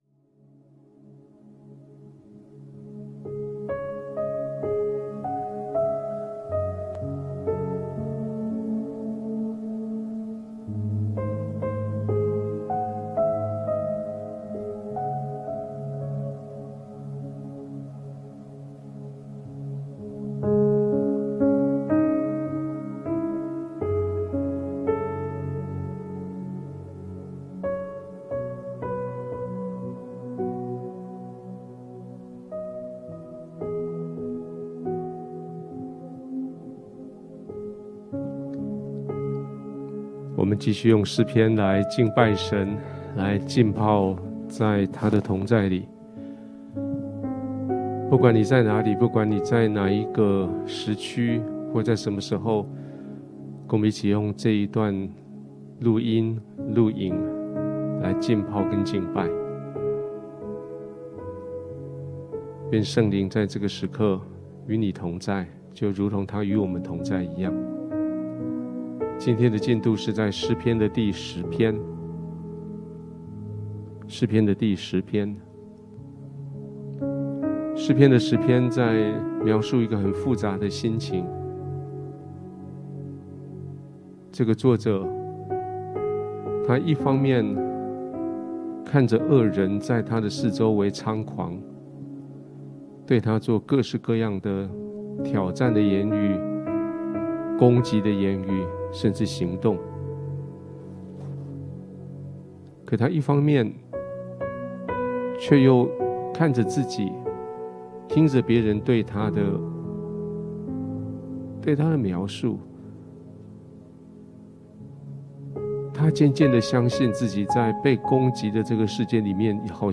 S琴與爐-敬拜浸泡-_-詩篇10.mp3